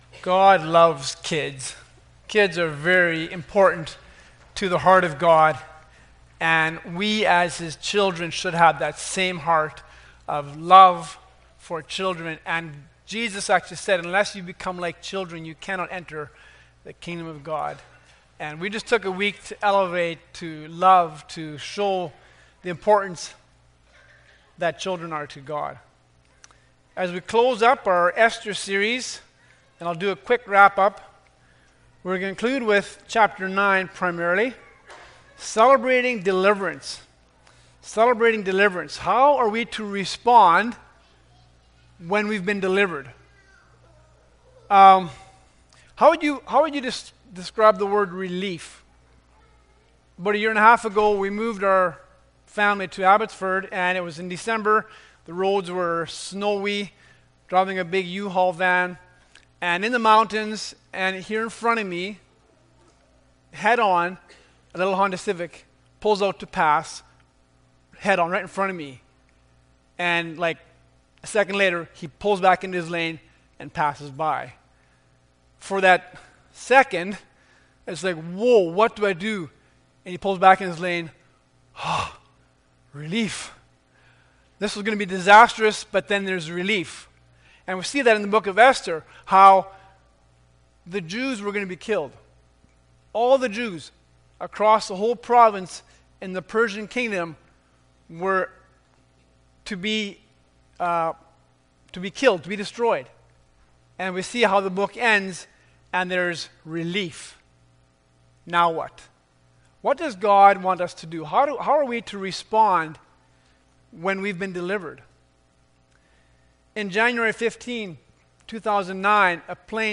Esther 9:20-28 Service Type: Sunday Morning Bible Text